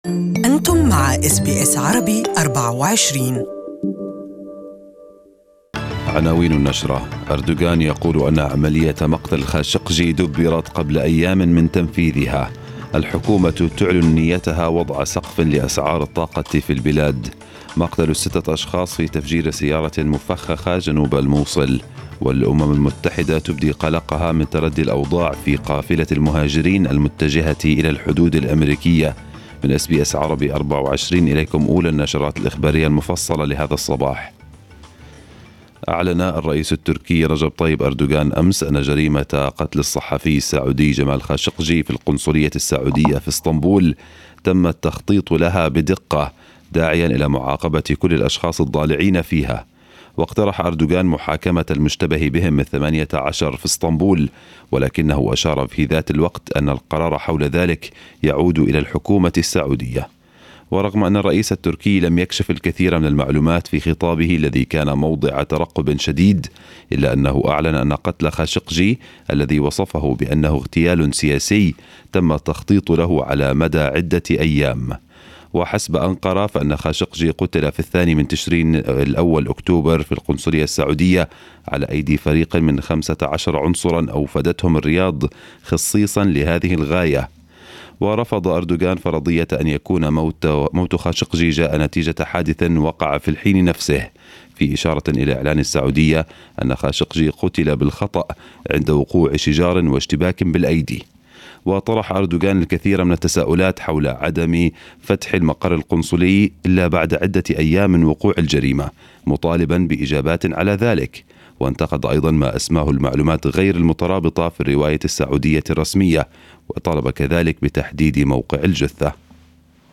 News bulletin of the morning